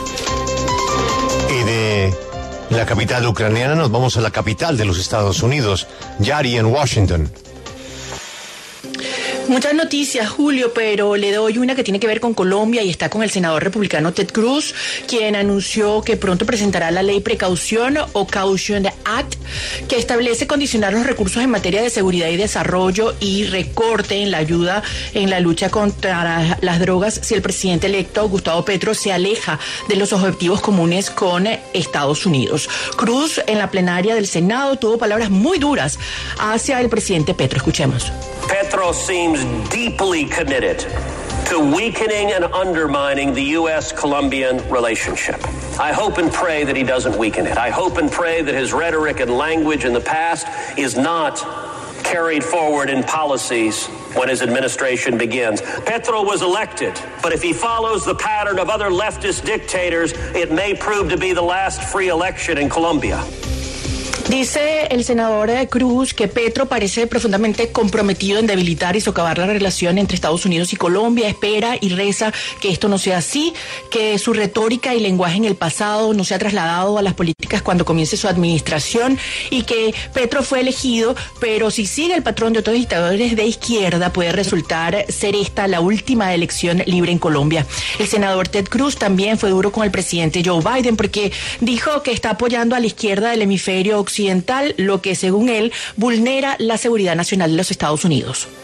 Durante su discurso, también lanzó algunas advertencias al presidente electo de Colombia en caso de afectar las relaciones históricas con Estados Unidos.